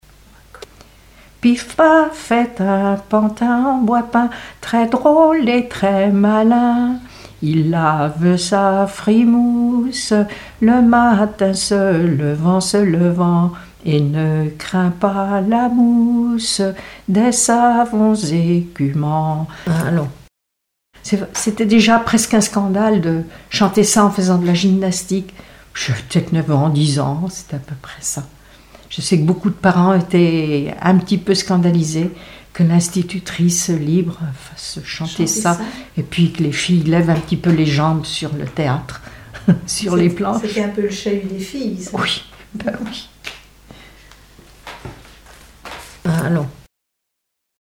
Mémoires et Patrimoines vivants - RaddO est une base de données d'archives iconographiques et sonores.
Genre brève
Pièce musicale inédite